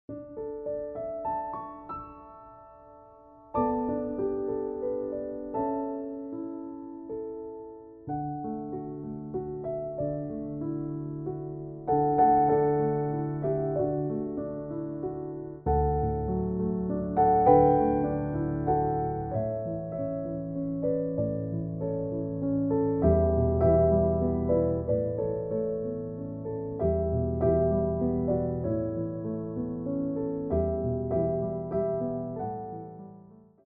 33 Tracks for Ballet Class.
Pliés 1
3/4 (16x8)